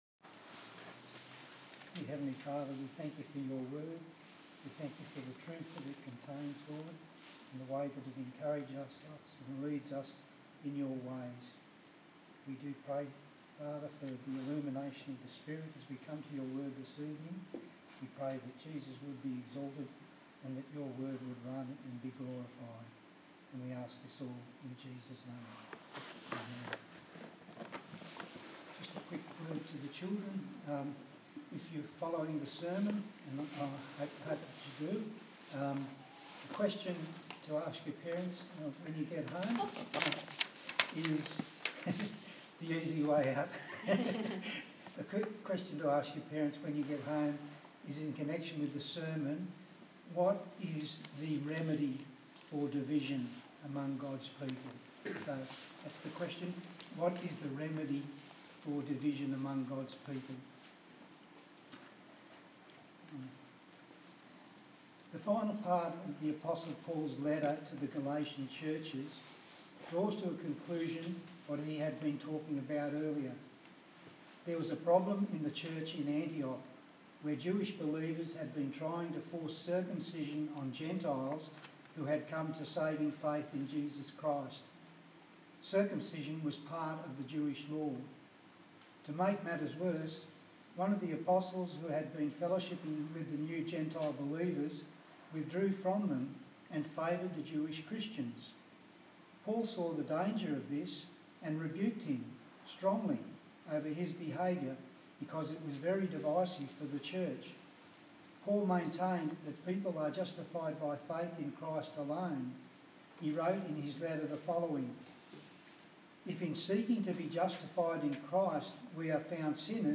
A sermon on Ezekiel and Galatians